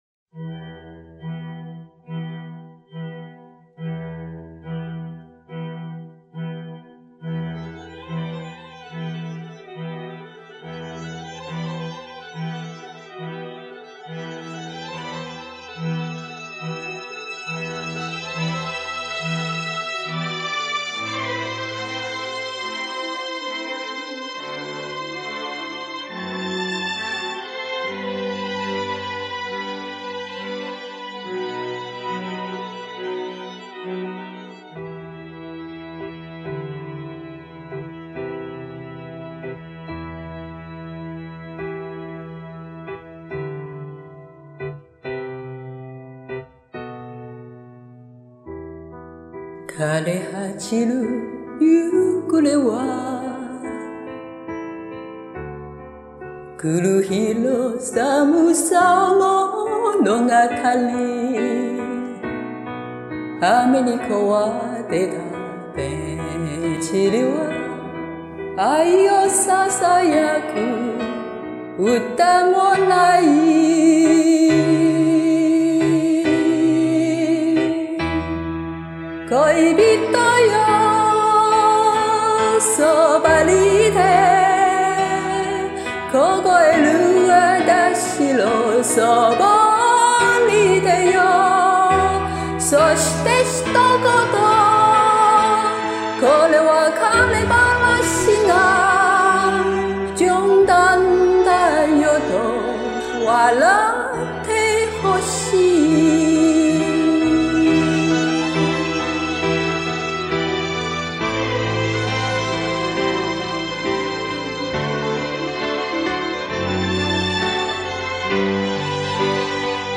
唱出了'尖',嗓尖心尖尽管有些颤但味道就有了
唱得很地道很动情，意境迷离美丽，很好听！